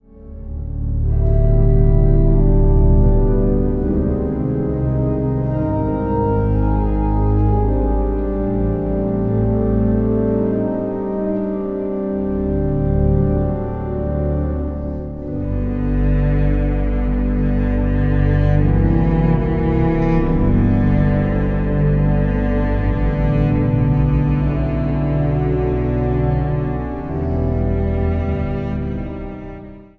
trompet/bügel
orgel.
Instrumentaal | Cello
Instrumentaal | Hobo
Instrumentaal | Trompet